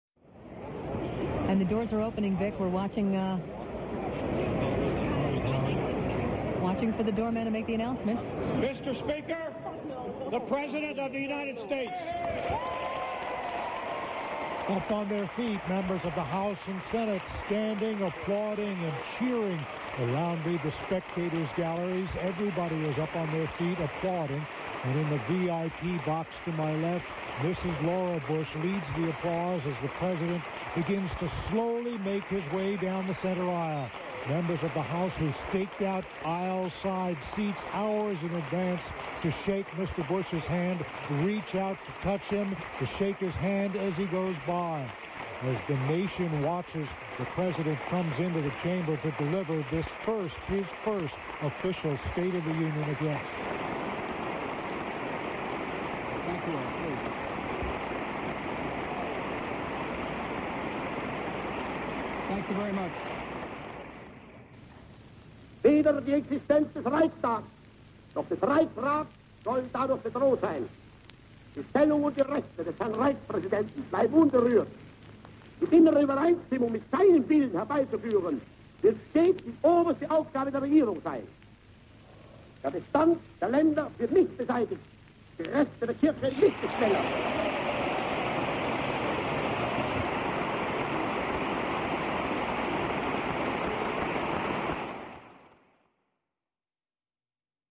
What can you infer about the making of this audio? Source Material: State of the Union Address, February 27, 2001 Hitler's contribution comes from remarks "about the Reichstag fire."